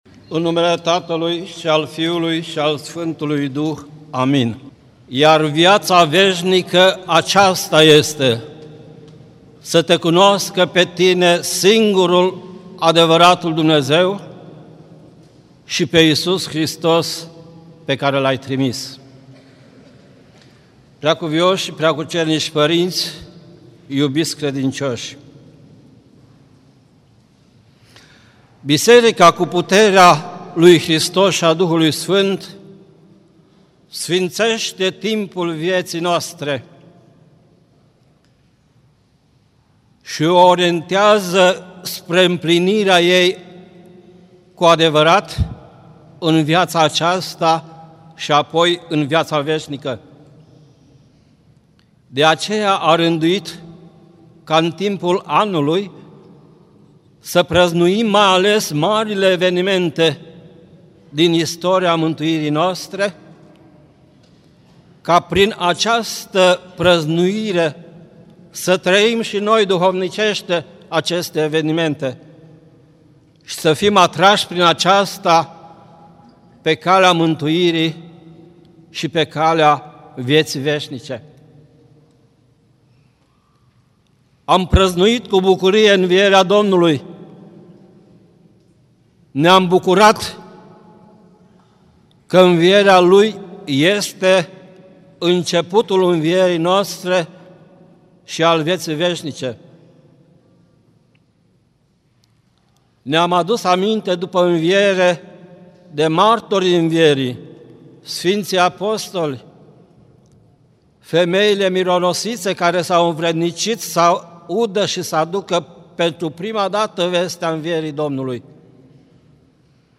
Predică la Duminica a 7-a după Paști
Cuvinte de învățătură Predică la Duminica a 7-a după Paști